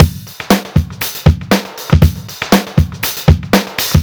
What It is, is essentially an audio fattening plugin that doesn’t only bring out the harmonics but also focuses on controlling and leveling them.
Carefully designed presets to add YOUR touch to any loop!
Listen below what we were able to do to this loop with just a preset:
With Slam Dawg: